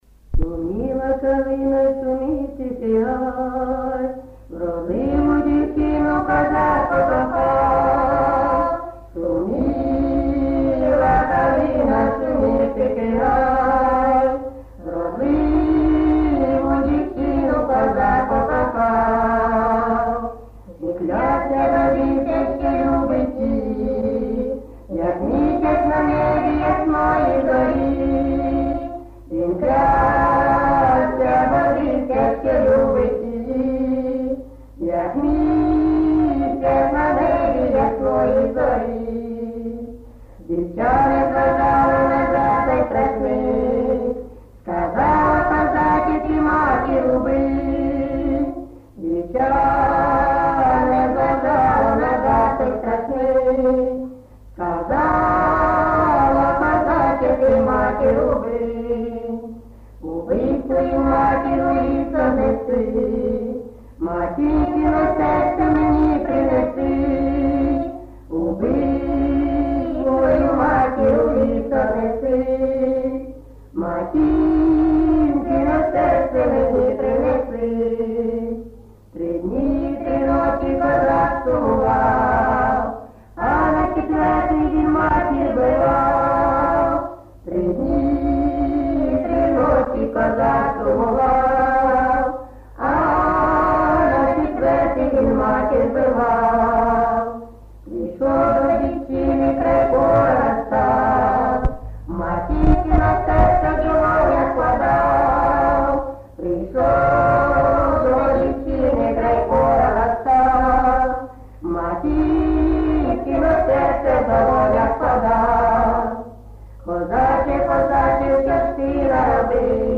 Місце записус. Золотарівка, Сіверськодонецький район, Луганська обл., Україна, Слобожанщина